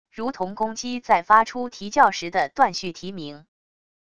如同公鸡在发出啼叫时的断续啼鸣wav音频